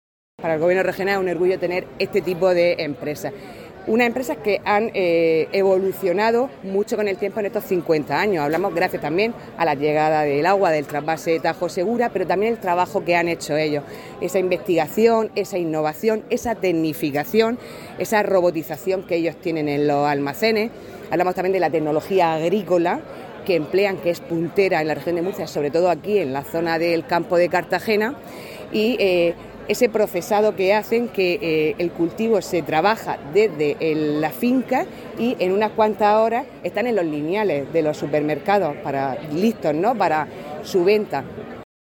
Contenidos Asociados: Declaraciones de la consejera de Agua, Agricultura, Ganadería y Pesca, Sara Rubira, sobre la Sociedad Agraria de Transformación (S.A.T.) San Cayetano (Documento [.mp3] 450,15 KB) Destacados Conciliación laboral (SMAC) e-Tributos Pago a Acreedores Participación ciudadana Canal Mar Menor © Todos los derechos reservados.